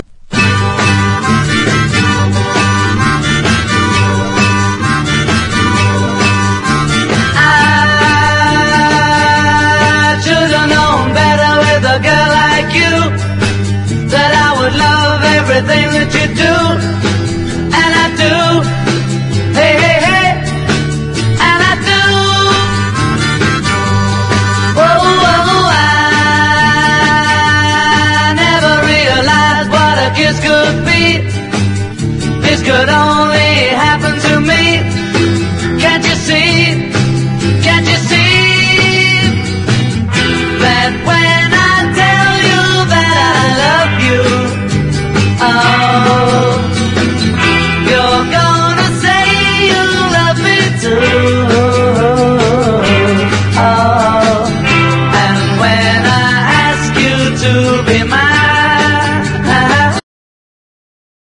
ROCK / 60'S / BLUES ROCK / BLUES
フィルモア・オーディトリアムで三日間に渡って行われた奇跡のセッション！